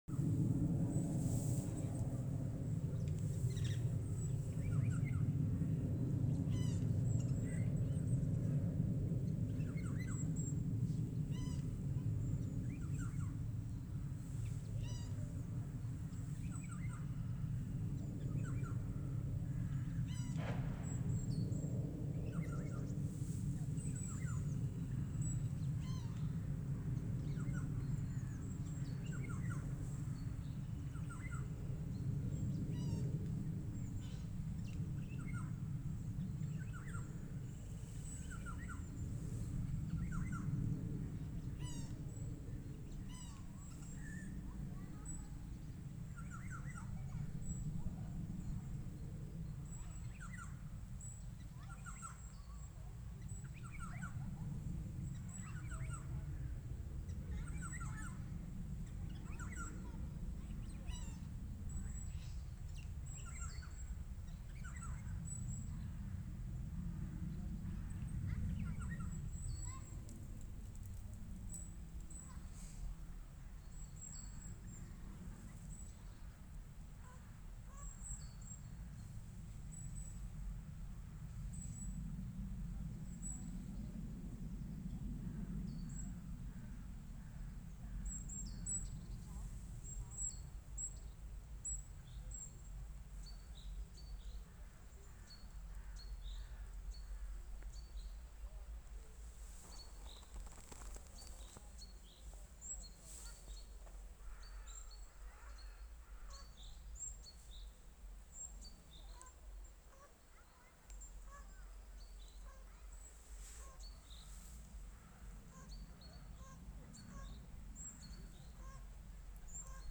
Eurasian Jay, Garrulus glandarius
Notes/Atdarina vālodzi, pēc tam meža pūci. Audio failu klausoties skaņa jāuzgriež uz maksimālo lai labi varēt dzirdēt.